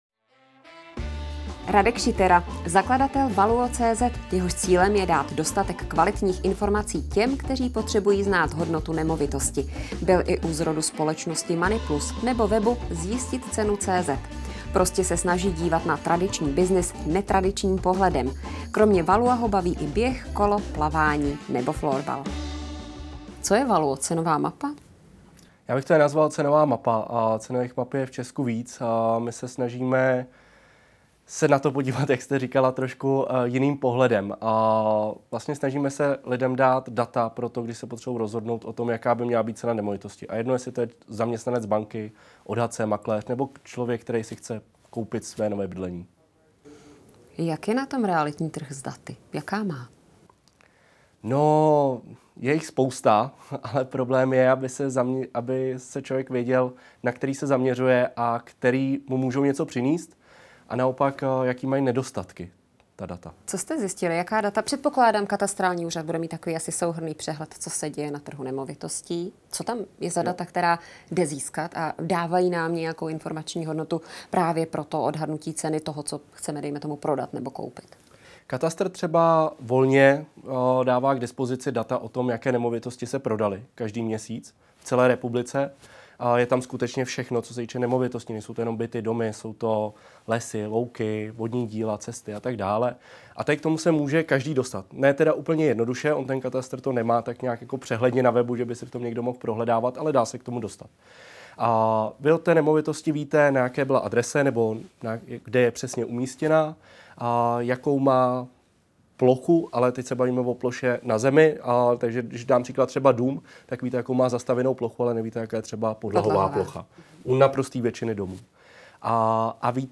Rozhovor